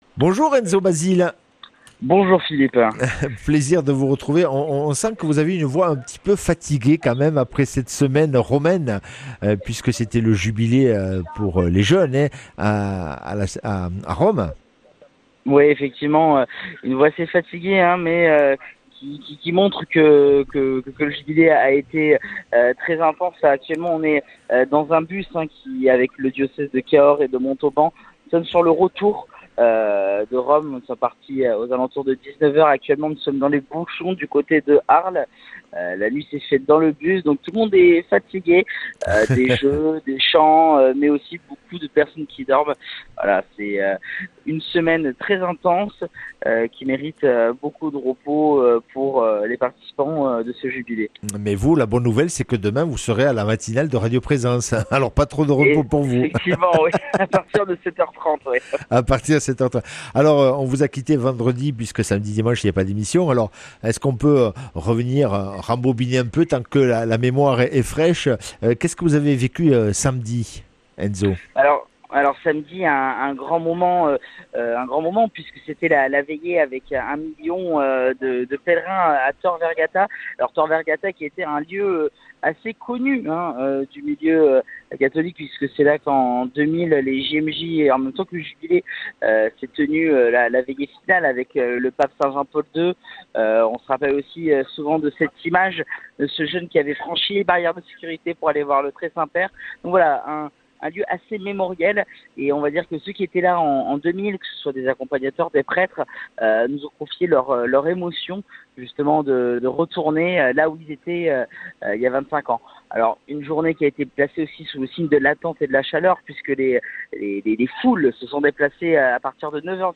Interview et reportage